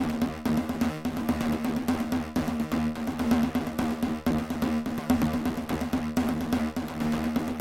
华尔道夫布洛费尔德 Arp Distorted 126BPM
描述：华尔道夫布洛菲德琶音失真126BPM
Tag: 126 bpm Electronic Loops Synth Loops 1.28 MB wav Key : D